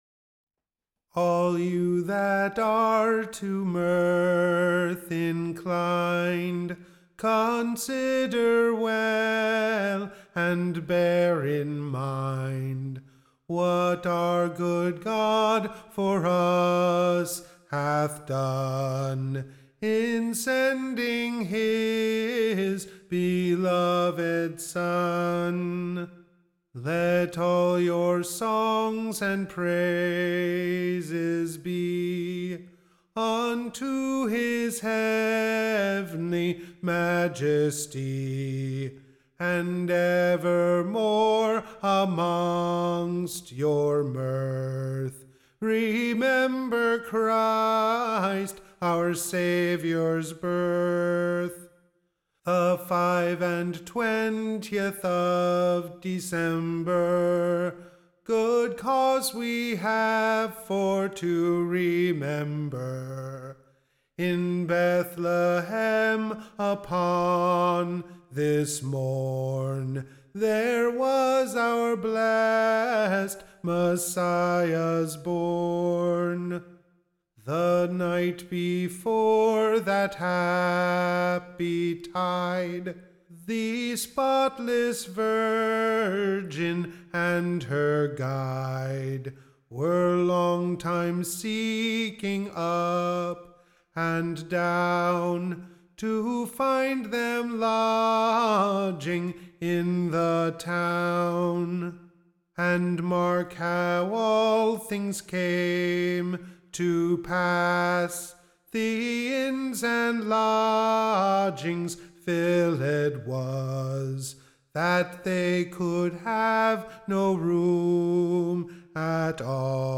Recording Information Ballad Title The Sinners Redemption.